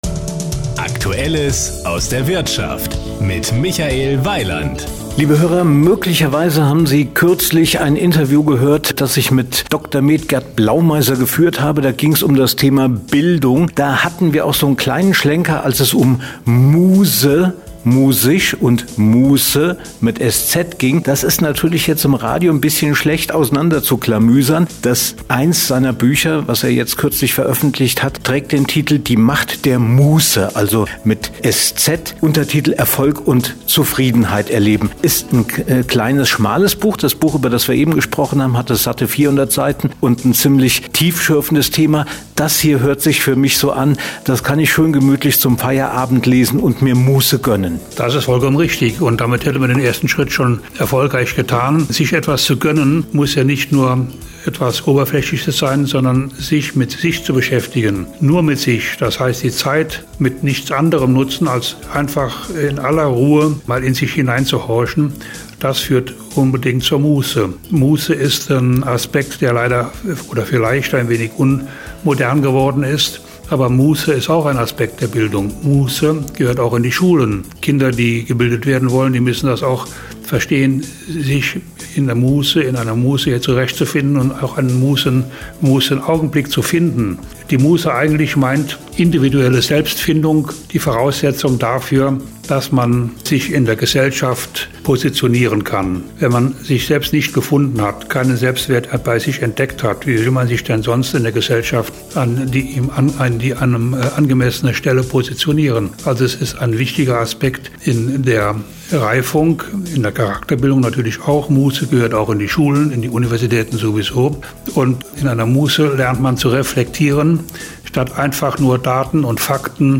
Sie sind hier: Start » Interviews » Interviews 2019